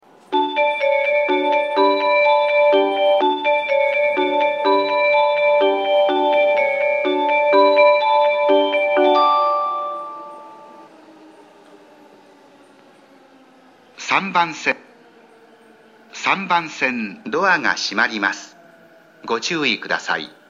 発車メロディーフルコーラスです。